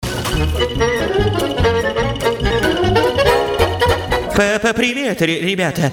веселые
голосовые
цикличные
Забавный короткий рингтон